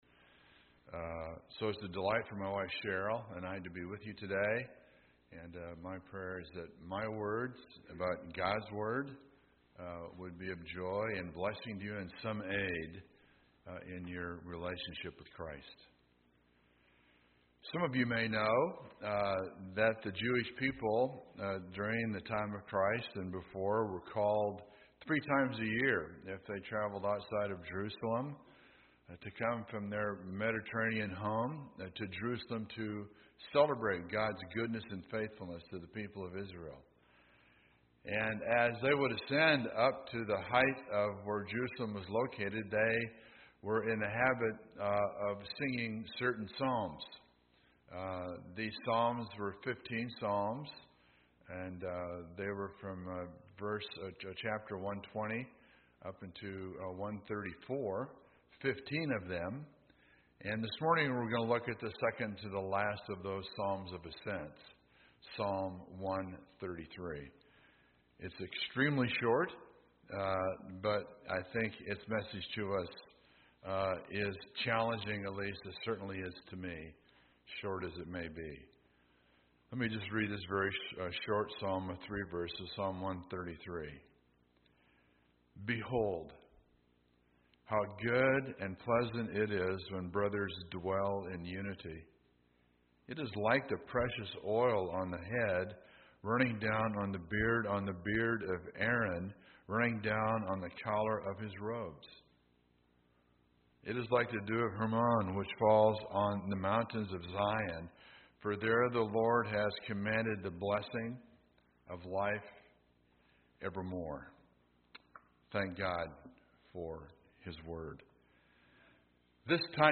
Sermons -